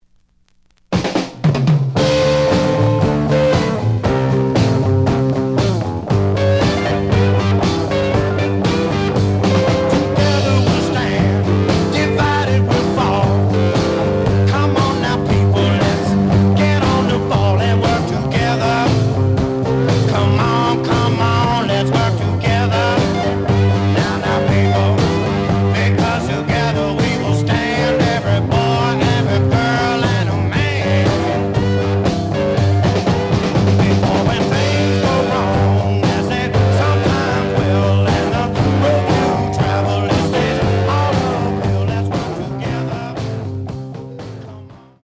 The audio sample is recorded from the actual item.
●Genre: Rock / Pop
Slight noise on A side.